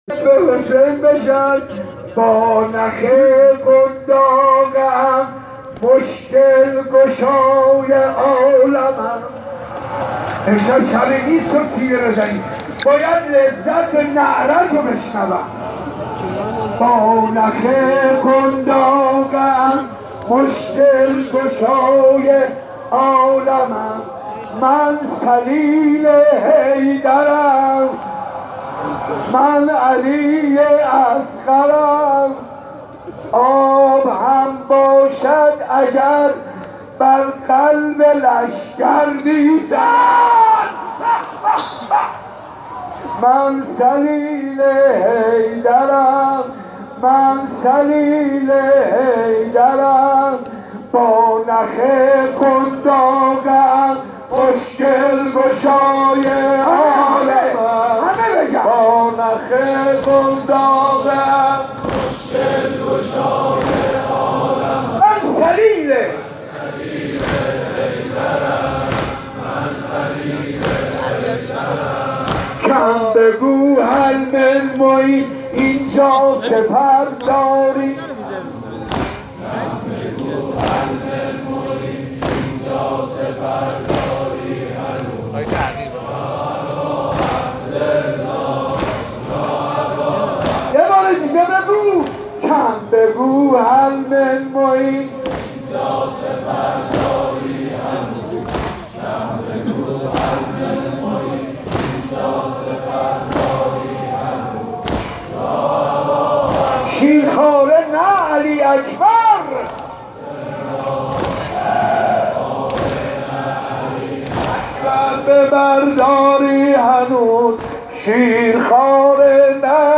شب هفتم محرم، صدای گریه نوزدان صحن مسجد ارک را پر کرد
عقیق : هفتمین شب از مراسم عزاداری سیدالشهدا در مسجد ارک تهران برگزار شد و هزاران نفر برای شیرخوار کربلا اشک ریختند.
در خلال مداحی حاج منصور ، چندین طفل شیرخوار را به مجلس آوردند که گریه آنها حال مجلس را دگرگون کرد.
صوت مراسم